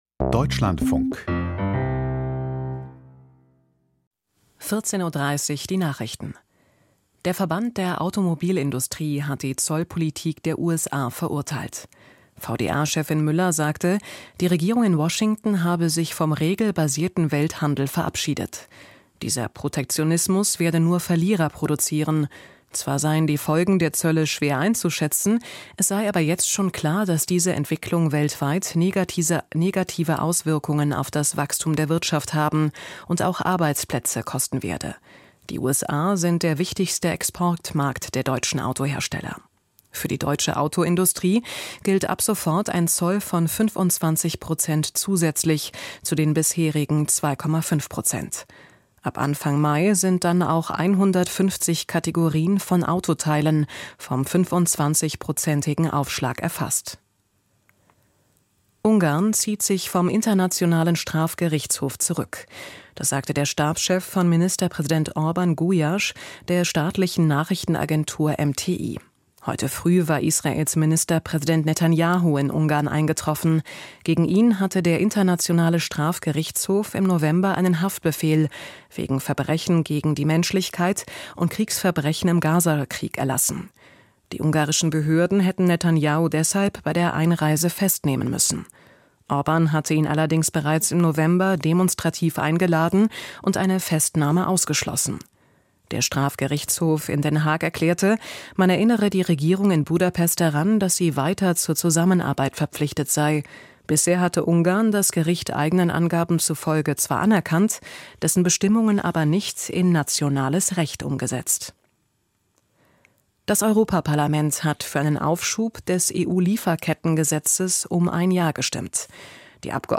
Die Deutschlandfunk-Nachrichten vom 03.04.2025, 14:30 Uhr